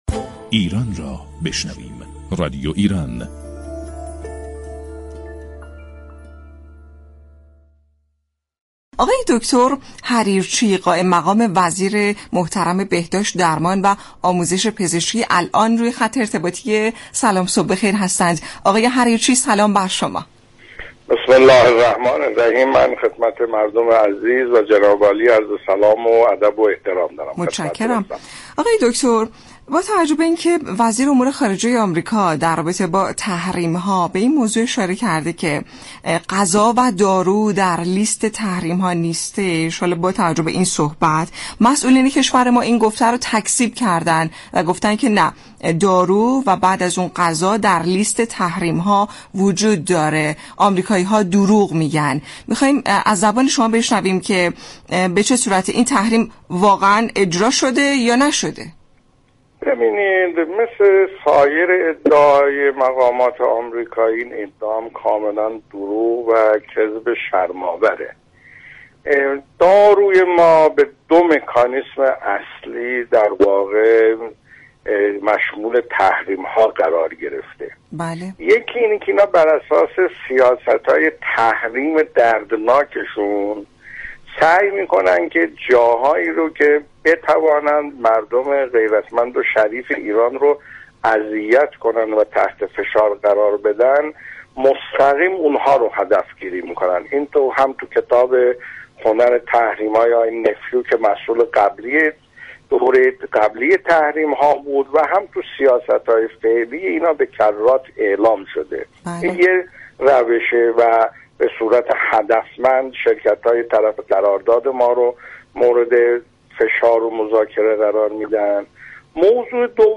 دكتر ایرج حریرچی قائم مقام وزیر بهداشت و سخنگوی این وزارتخانه در برنامه «سلام صبح بخیر» رادیو ایران گفت